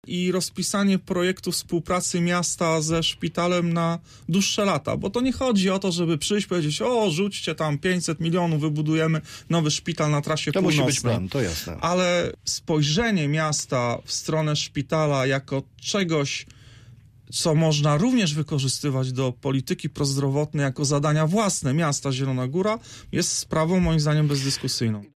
Czy miasto powinno zwiększyć dotację dla Szpitala Uniwersyteckiego? Pytaliśmy oto dziś w rozmowie Punkt 9 Adama Urbaniaka, radnego Platformy Obywatelskiej.